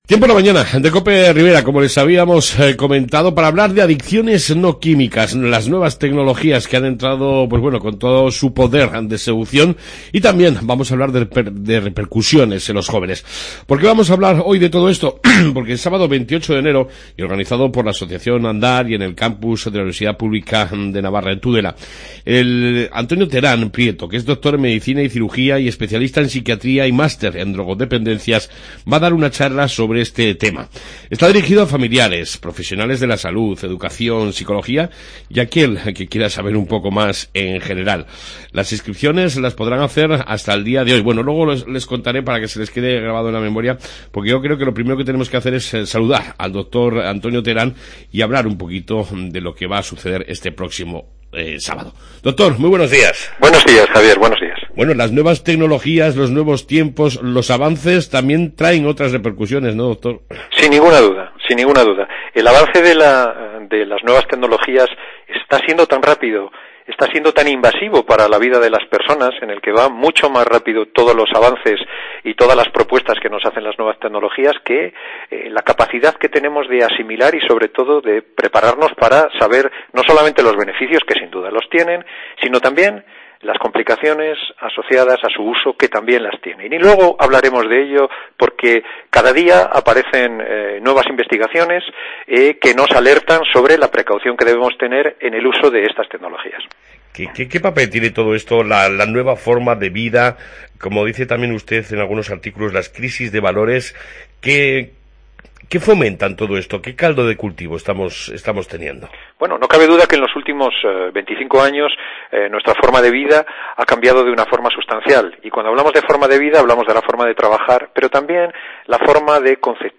Audios Tudela